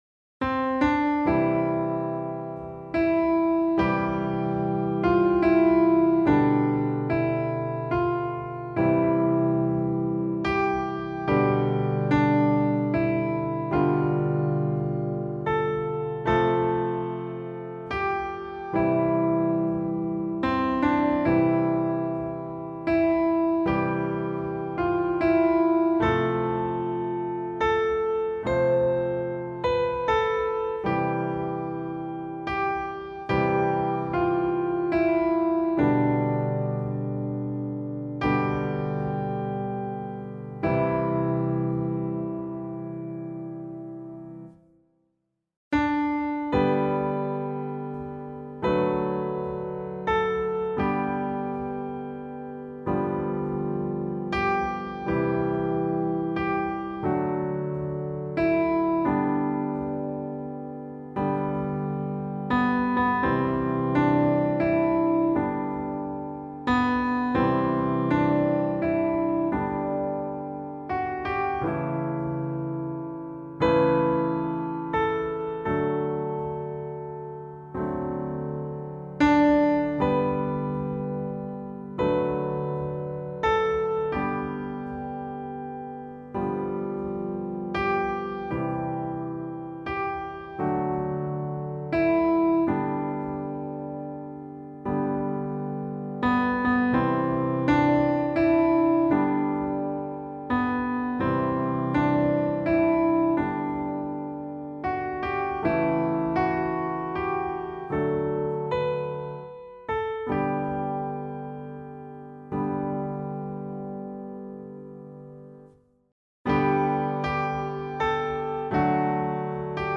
song samples